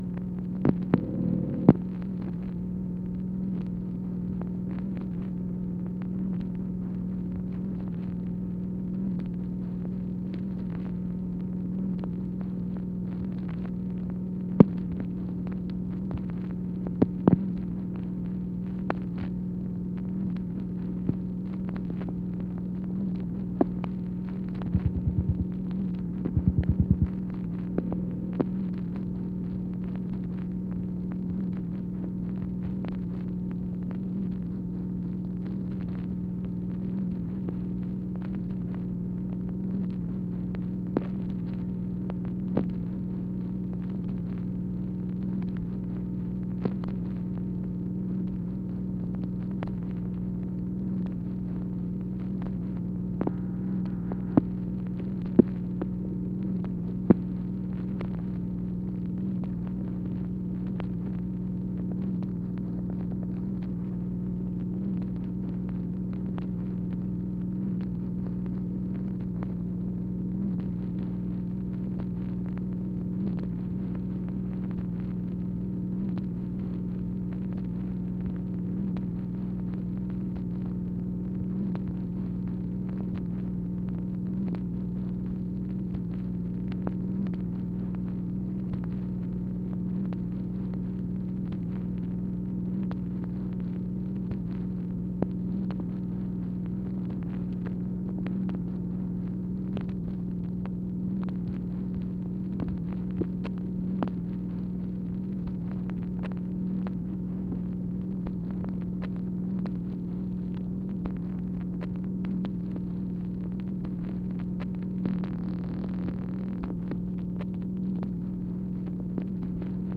MACHINE NOISE, January 6, 1964
Secret White House Tapes | Lyndon B. Johnson Presidency